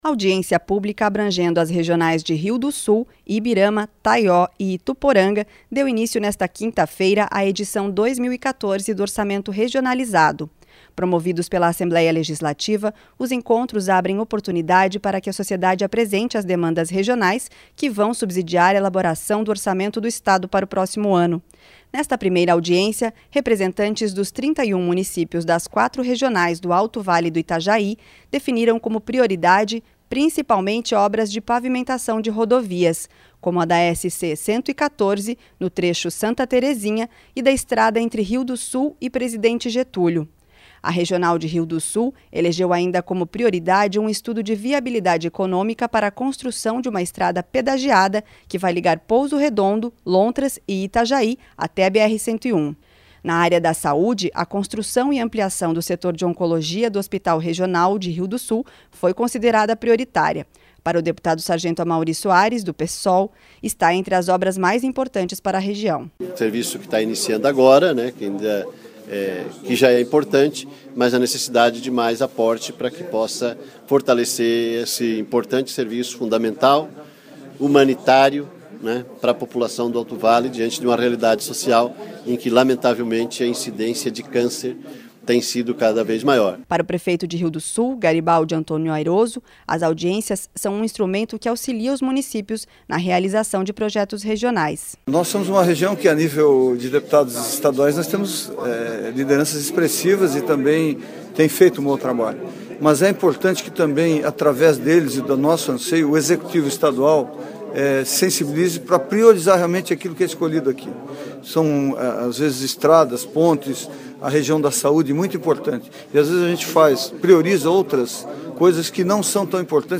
Entrevistas: deputado sargento Amauri Soares (PSOL); Garibaldi Antônio Ayroso, prefeito de Rio do Sul; deputado Gilmar Knaesel (PSDB), presidente da comissão de finanças da Assembleia Legislativa.